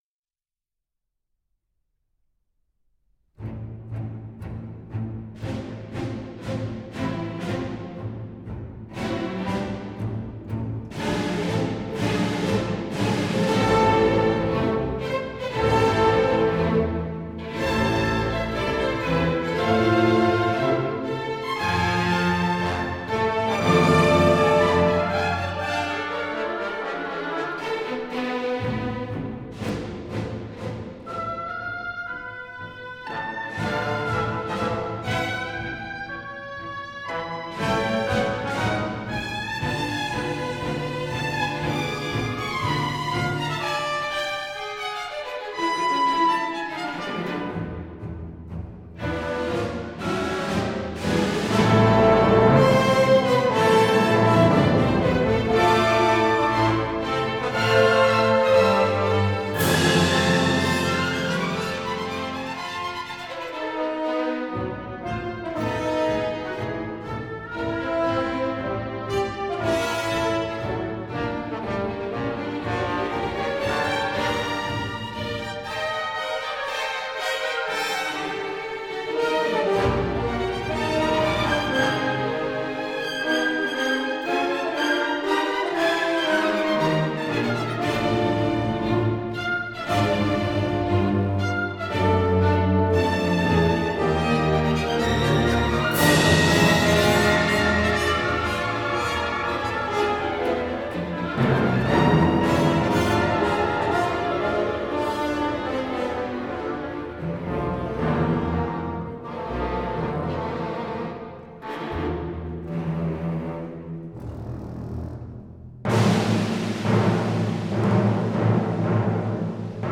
Tonhalle Orchestra Zurich.David Zinman.
Gustav Mahler (1860-1911): Symphony no. 6 in A minor. I. Allegro energico, ma non troppo.